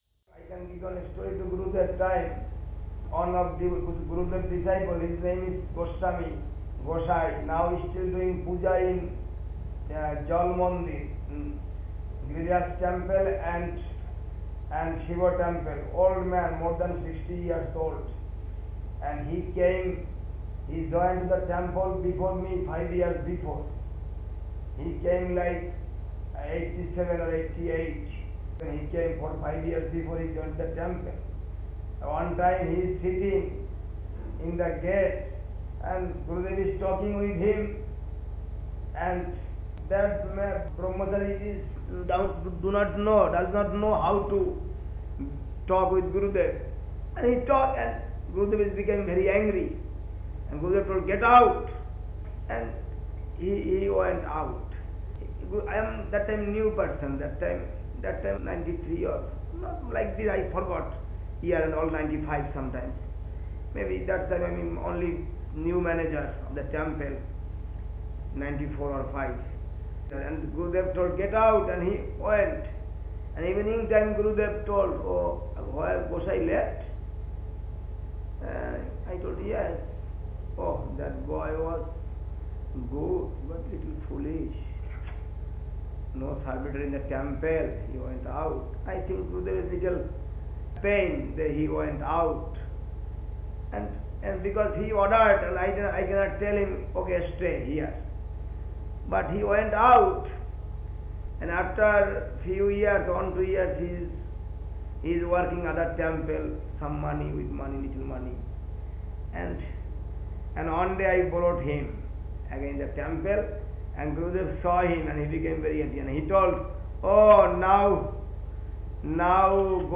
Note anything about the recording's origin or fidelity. Speaking to devotees in London